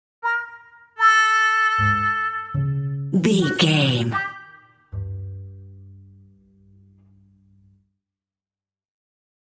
Atonal
DAW, orchestral samples
bright
joyful
chaotic
frantic
bouncy
percussion
flutes
oboe
strings
trumpet
brass